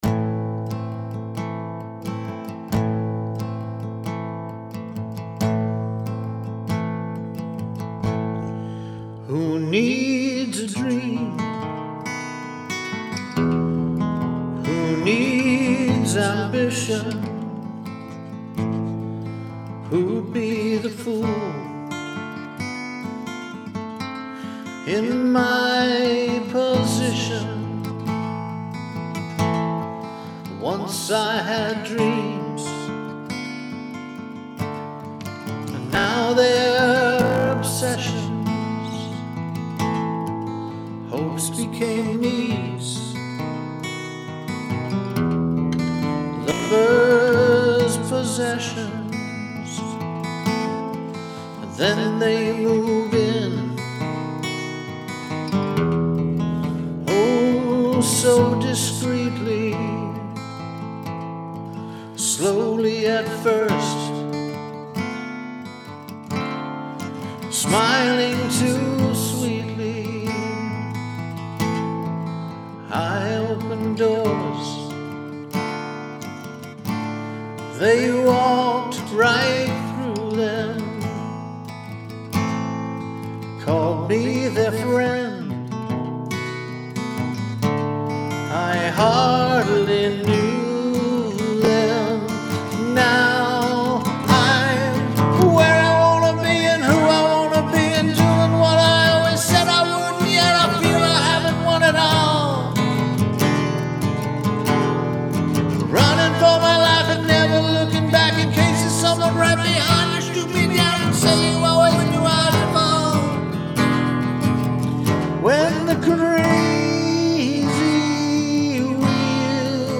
Haunting vocals that match the guitar’s vibe.